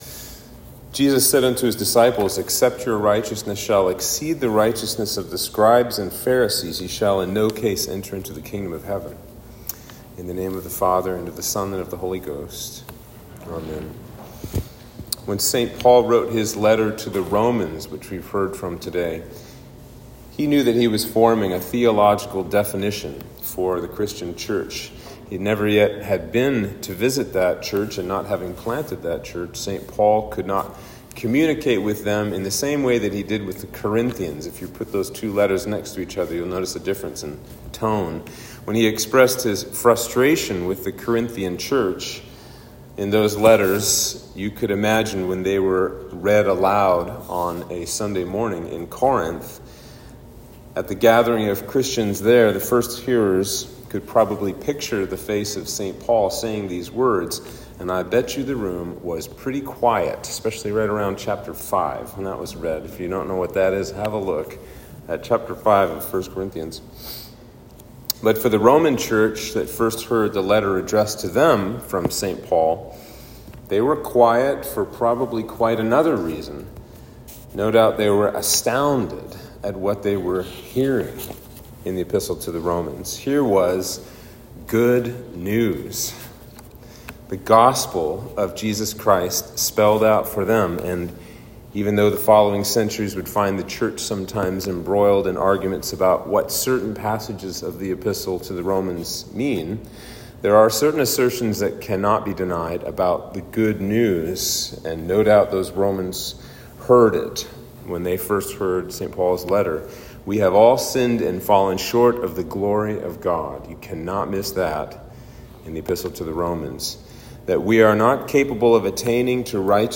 Sermon for Trinity 6